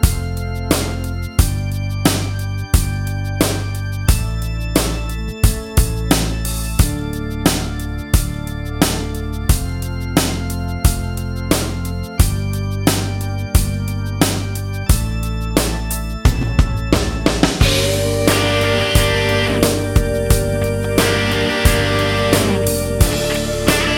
No Main Guitar Rock 5:33 Buy £1.50